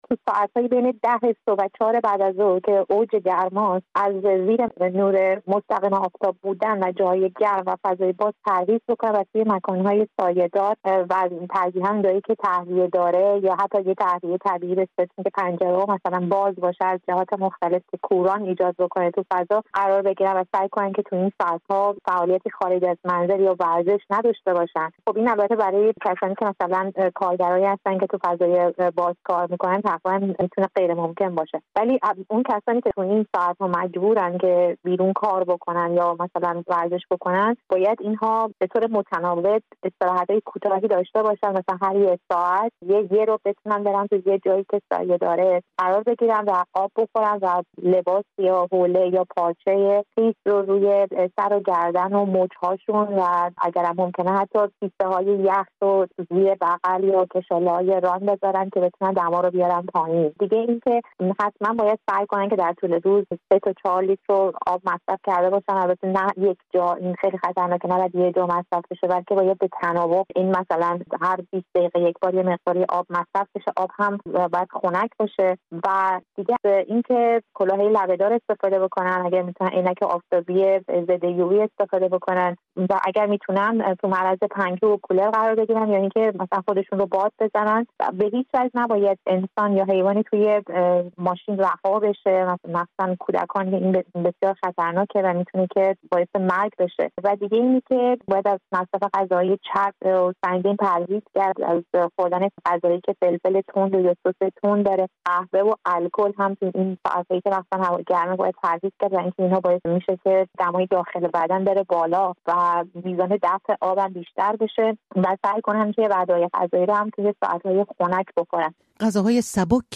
گفت‌وگو کرده‌ایم